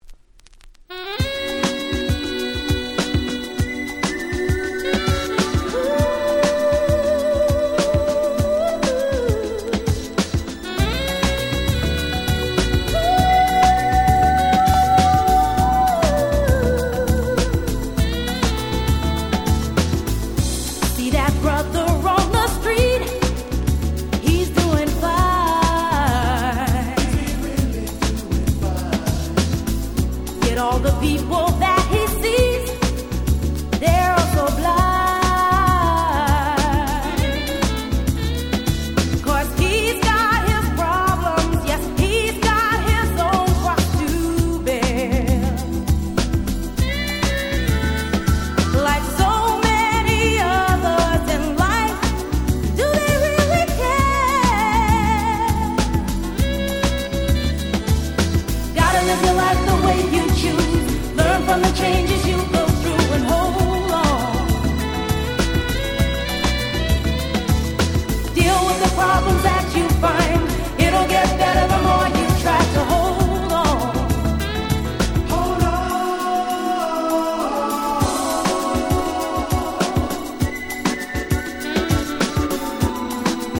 93' Nice R&B Album !!
90's キャッチー系 ボーカルハウス